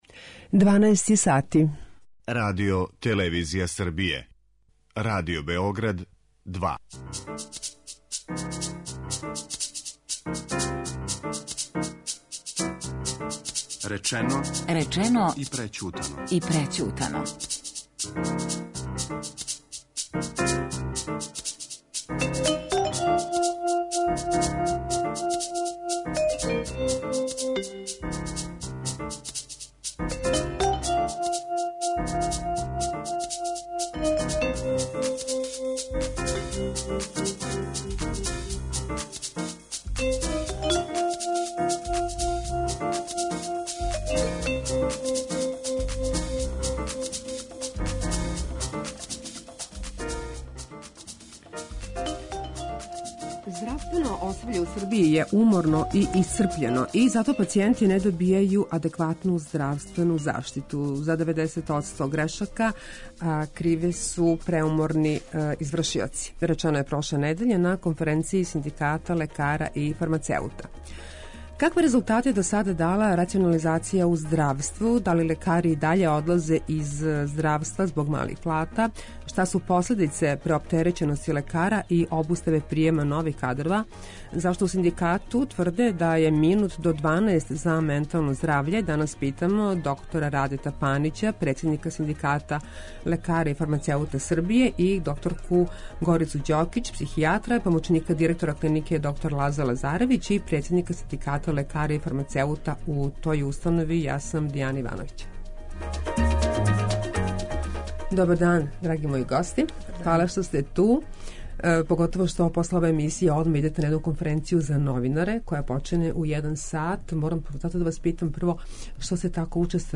Гостовање на Радио Београду 2, 17.10.2016.